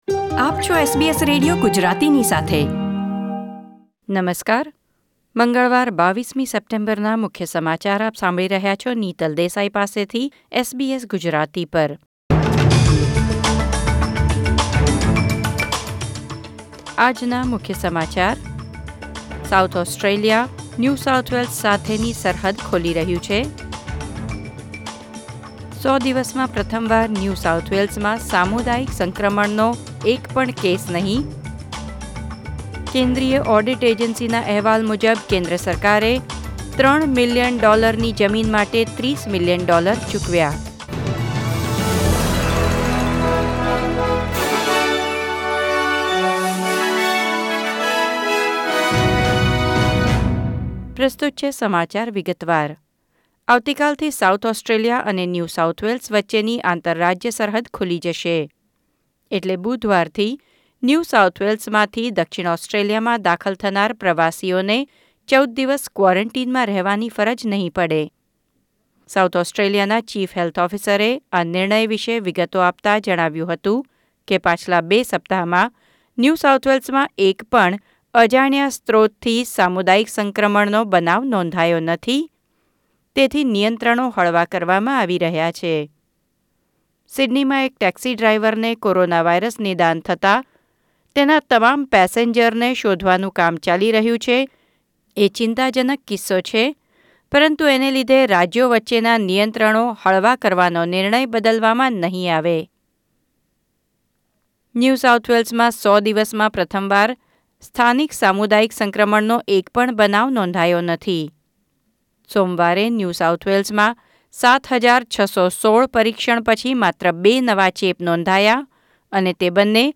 SBS Gujarati News Bulletin 22 September 2020